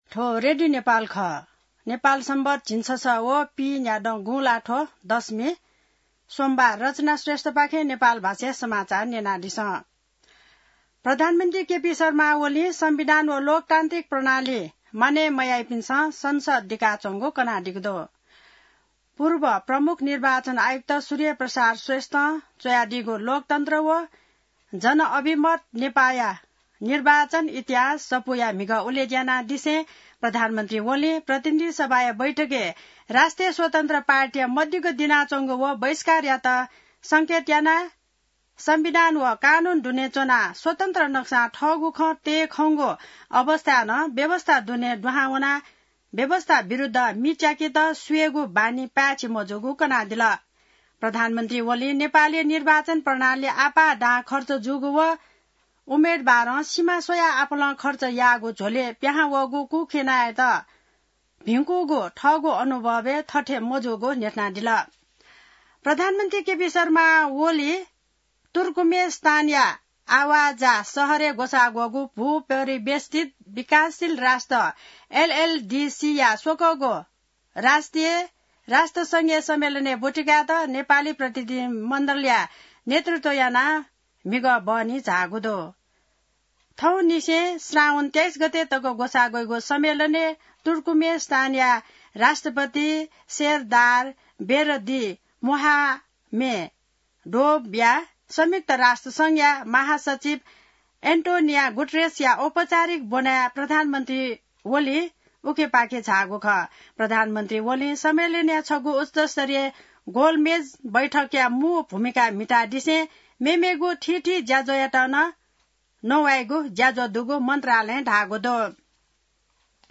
नेपाल भाषामा समाचार : १९ साउन , २०८२